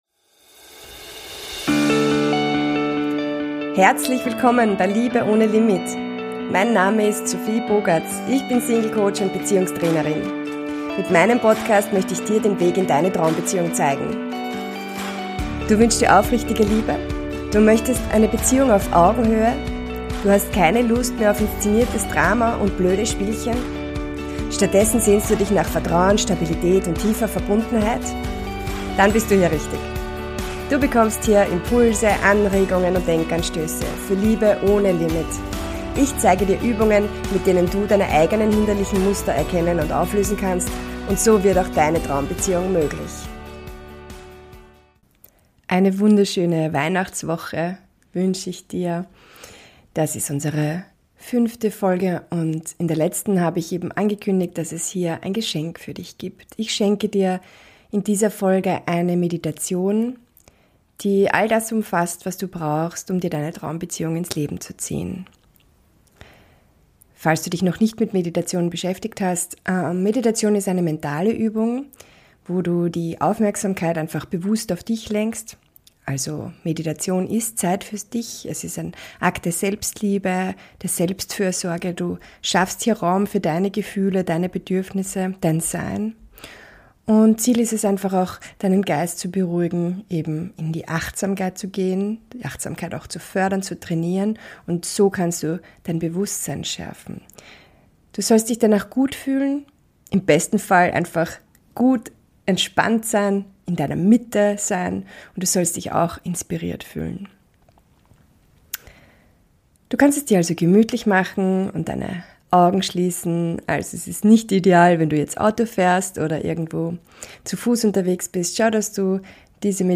Beschreibung vor 1 Jahr In dieser besonderen Weihnachtsfolge schenke ich dir eine kraftvolle Meditation mit starken Affirmationen, die dir Impulse für aufrichtige Liebe und deine Traumbeziehung schenken sollen. Lass dich von liebevollen Worten und stärkenden Gedanken begleiten, um die Liebe in deinem Leben zu manifestieren – genau so, wie du sie dir wünschst.